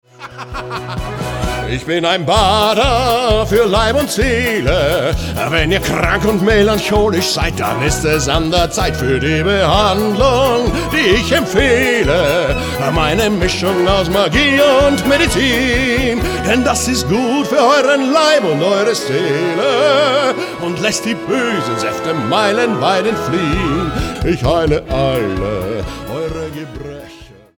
Showreel Musical